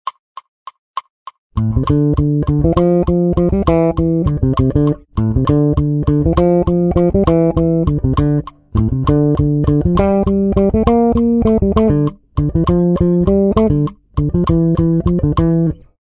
en bajo
para bajo eléctrico